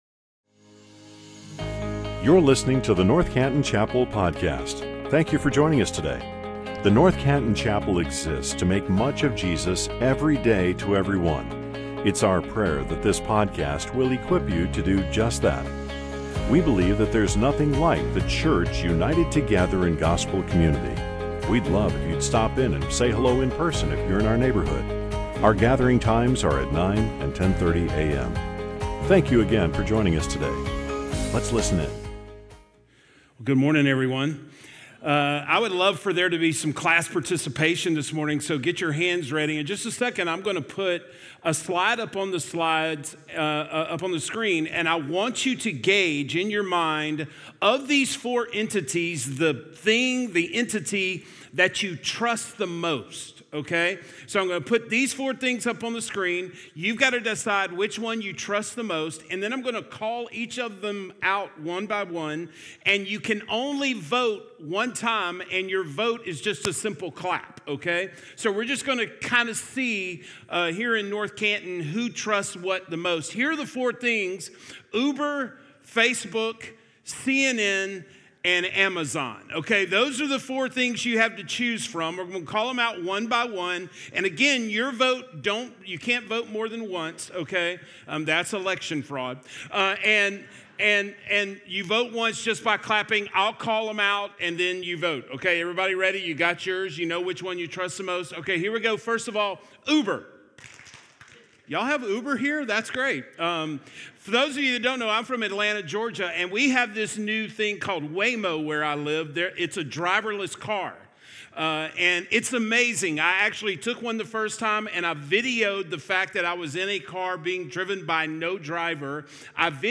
Annual event for middle and high school students.